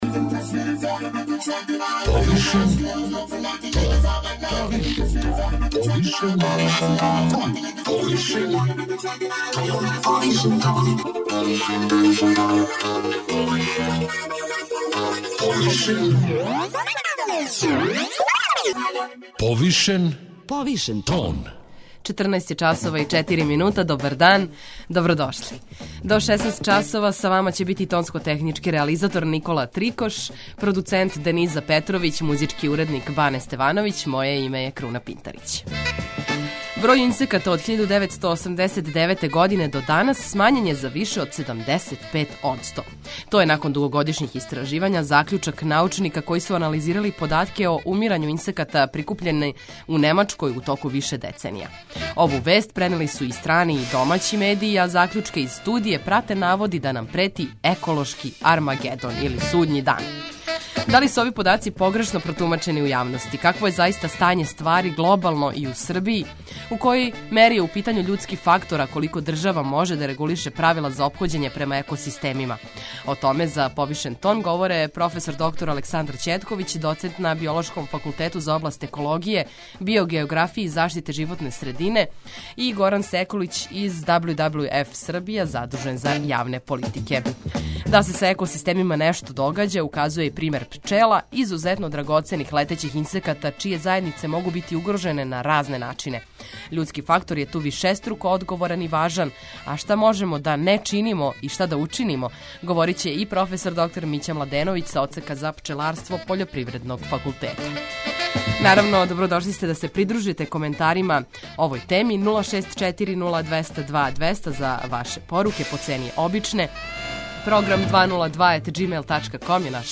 преузми : 52.98 MB Повишен тон Autor: Београд 202 Од понедељка до четвртка отварамо теме које нас муче и боле, оне о којима избегавамо да разговарамо aли и оне о којима разговарамо повишеним тоном.